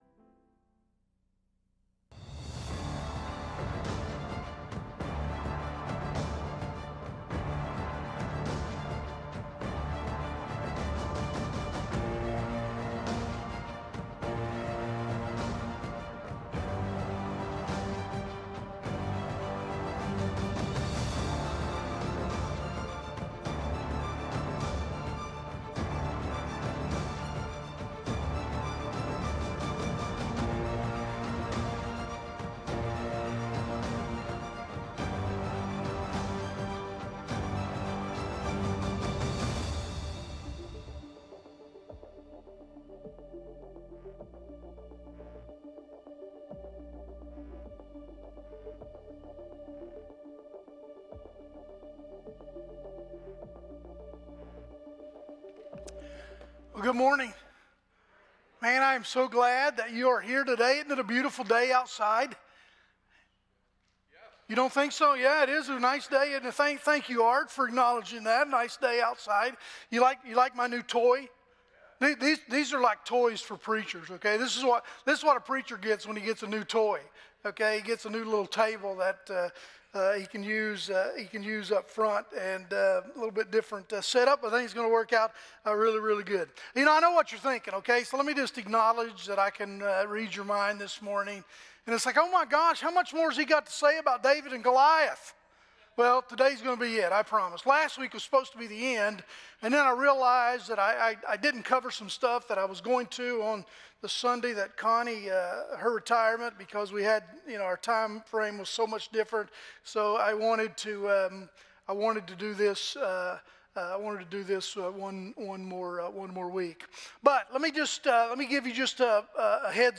Sermon Description